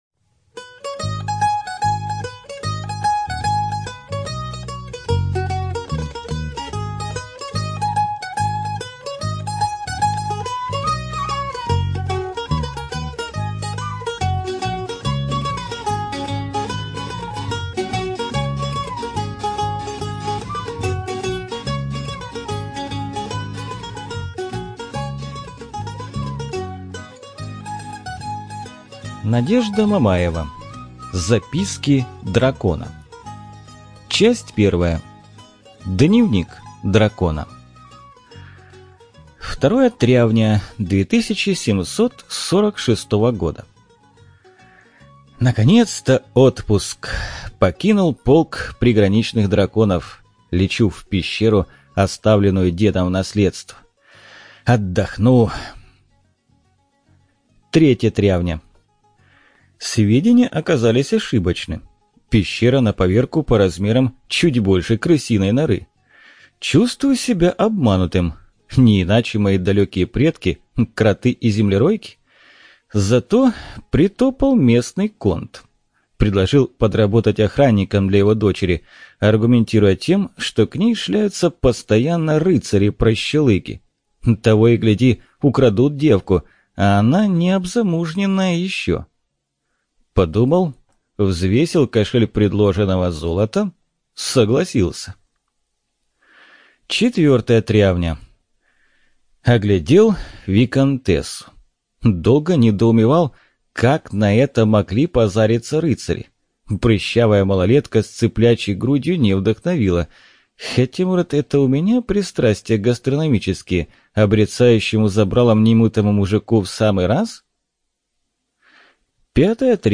Жанр: юмористическое фэнтези